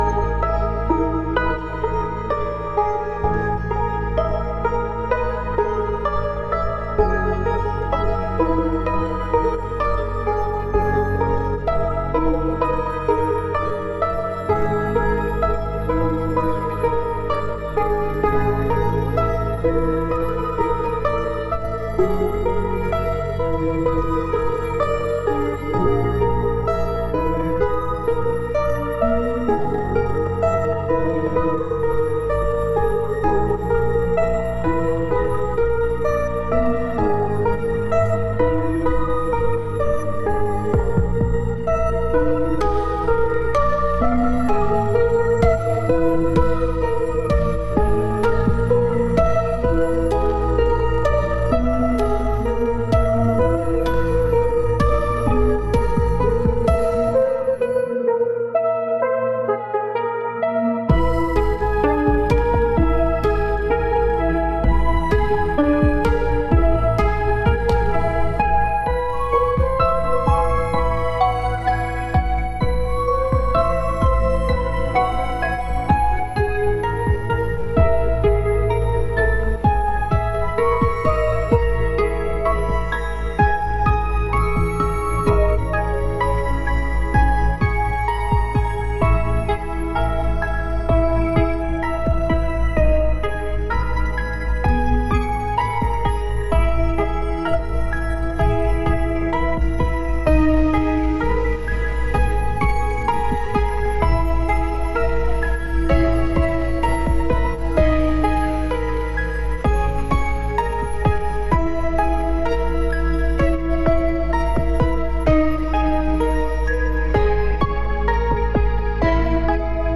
ambient.mp3